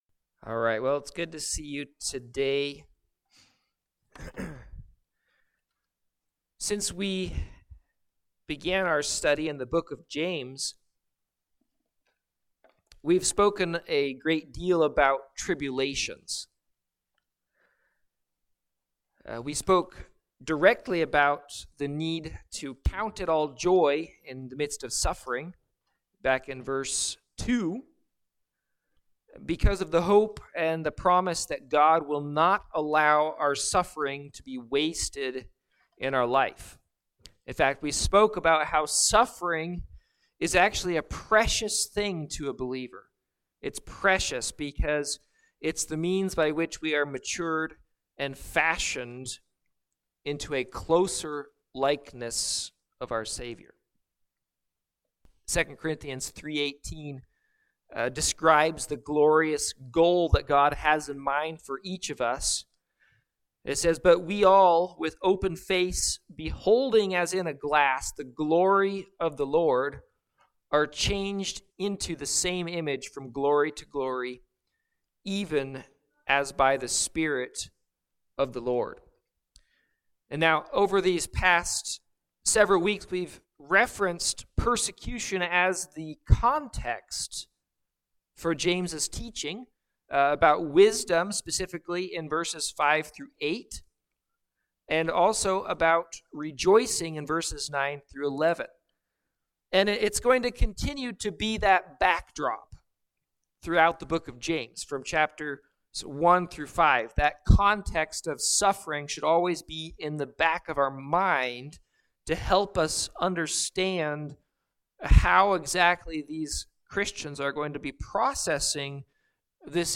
Service Type: Morning Sevice